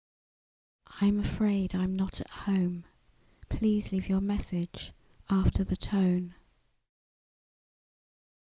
描述：女声说"恐怕我不在家，请在提示音后留言quot。
标签： 112 bpm Fusion Loops Vocal Loops 1.44 MB wav Key : Unknown
声道立体声